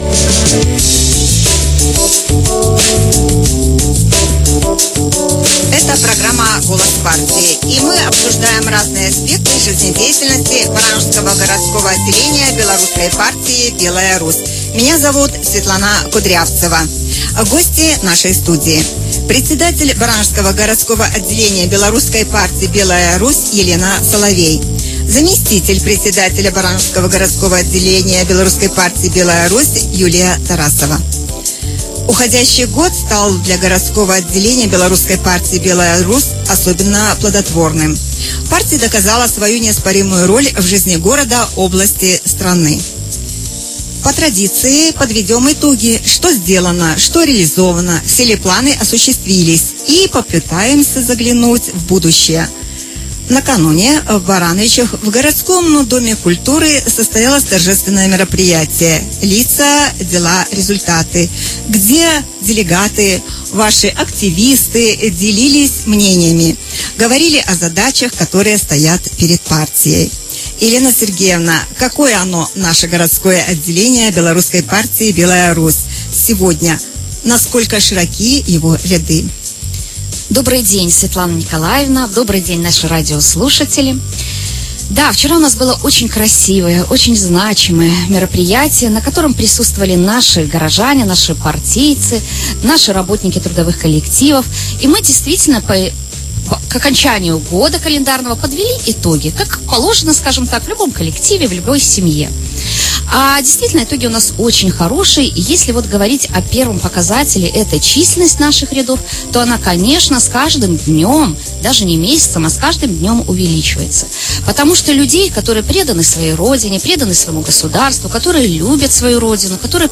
Сегодня мы подводим итоги: что сделано, что реализовано, все ли планы осуществились и попытаемся заглянуть в будущее. Гости студии